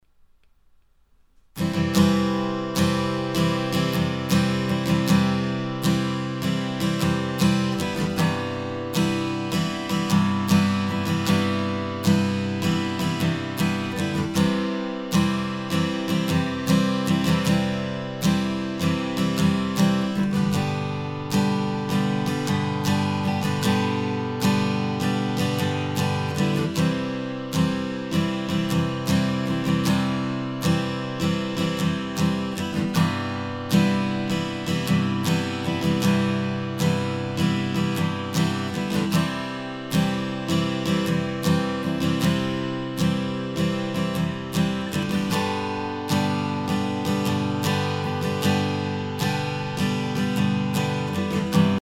Sample Guitar Track Ok, here is the sample acoustic guitar track.
With good headphones or studio monitors, you should notice the very high pitched ring in the track, as well as the overtones / reverb sounds I'm experiencing. The track also sounds quite dead and lacks presence, but I seem to be able to fix that by increasing the mid range EQ.
You'll notice the sounds I'm describing best when the "G" is played.